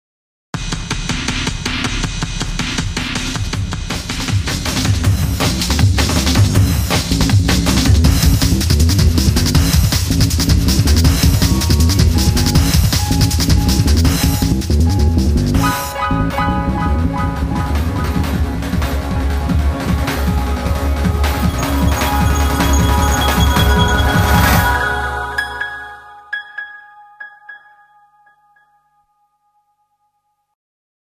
サンプリング多用して場面毎に細かく変化させてます。テンポ速いです。